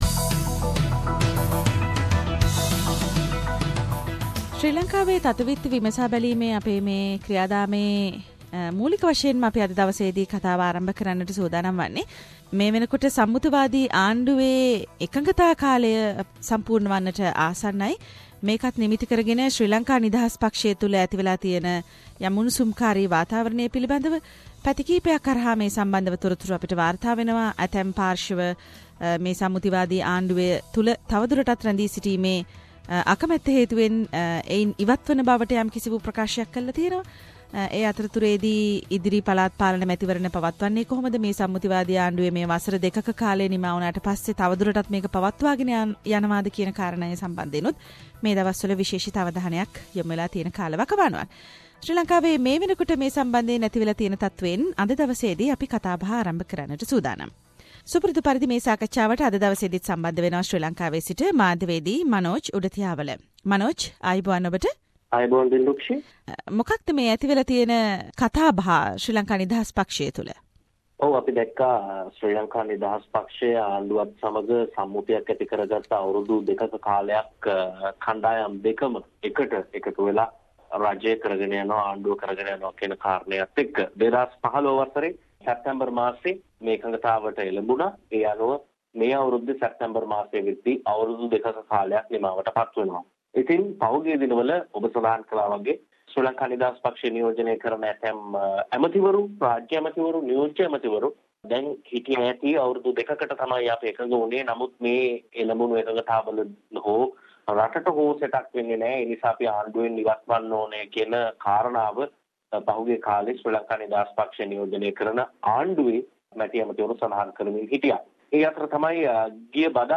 Weekly Sri Lankan political highlights - The comprehensive wrap up of the highlighted political incidents in Sri Lanka…..Senior Journalist - News and current affair